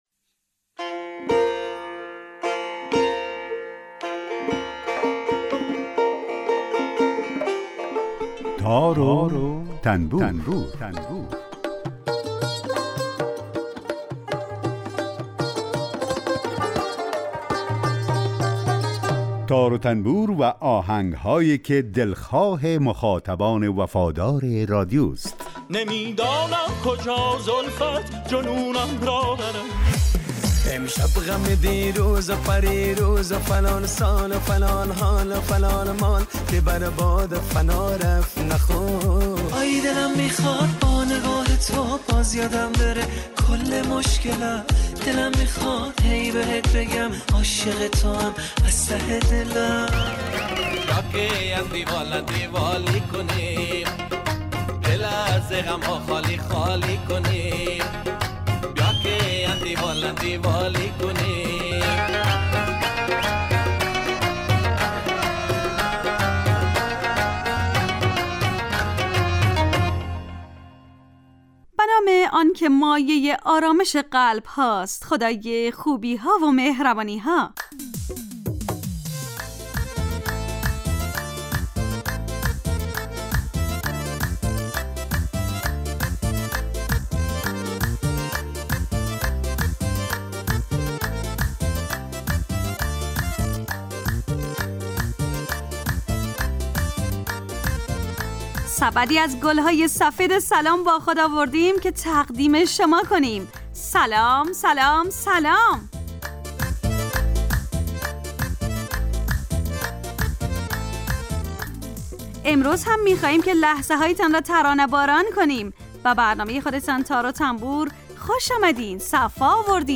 یک قطعه بی کلام درباره همون ساز هم نشر میکنیم